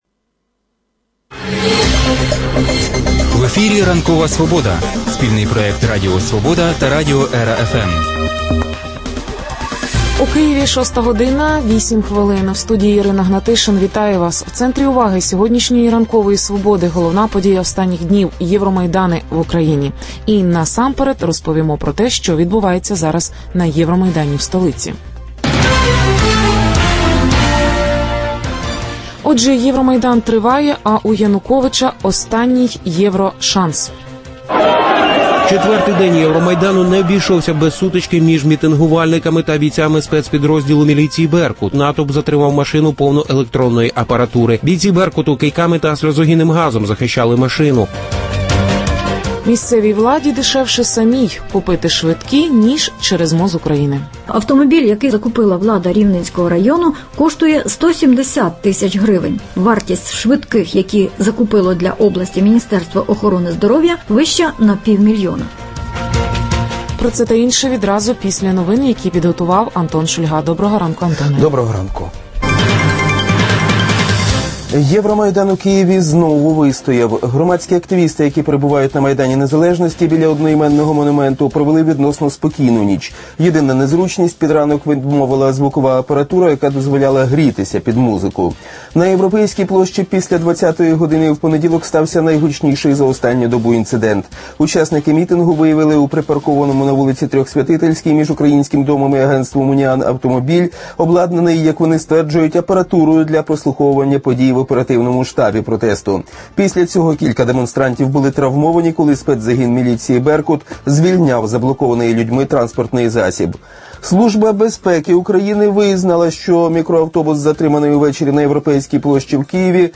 Прямі включення з Євромайдану в Києві. Європа дала Януковичу останній шанс на підписання угоди про асоціацію, чи скористається він ним? Амбіції місцевих політиків розвивають театри в Польщі та Росії.